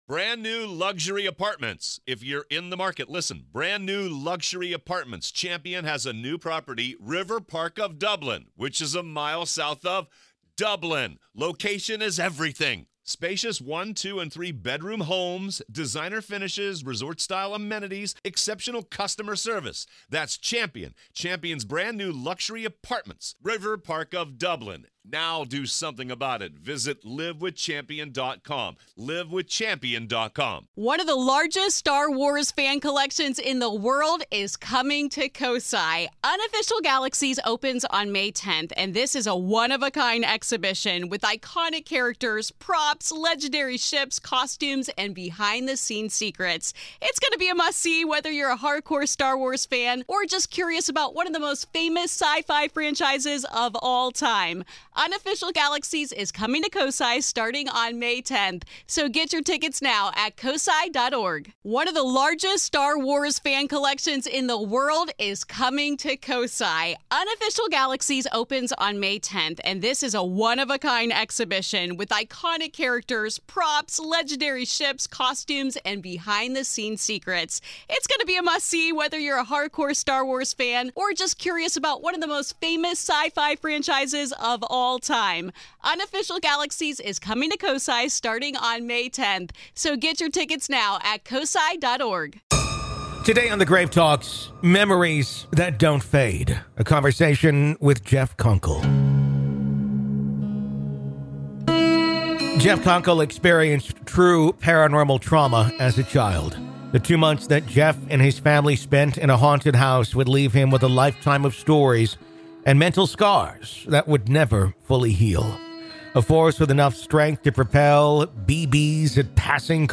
In part two of our interview